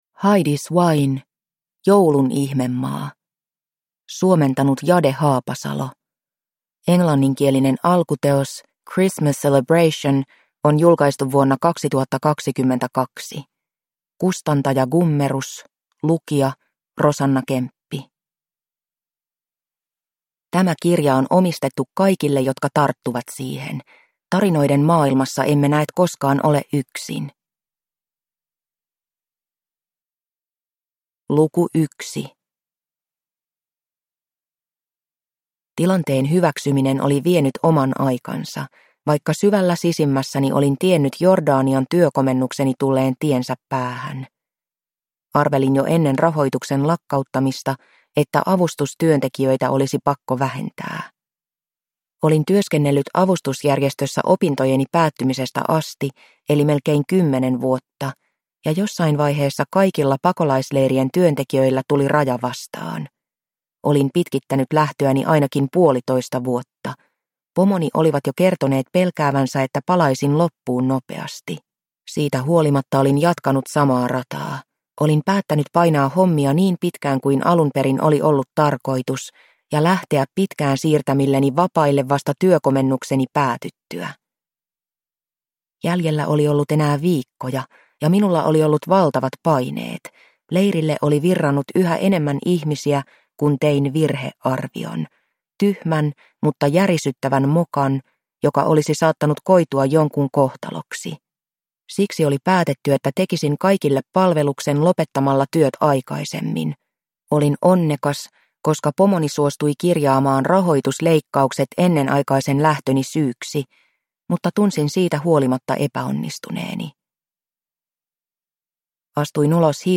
Joulun ihmemaa – Ljudbok – Laddas ner